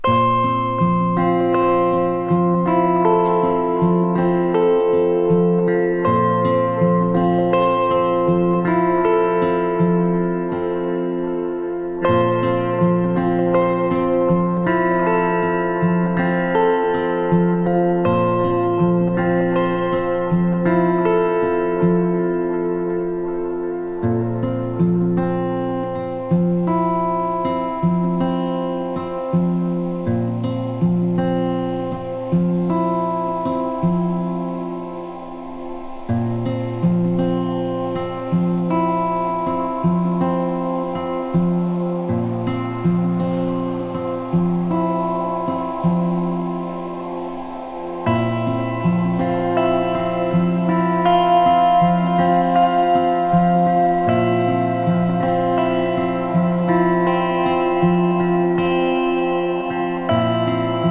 心地良いアコギと優しいVoが調和した楽曲、エレクトロニクスを織り交ぜた壮大な楽曲まで！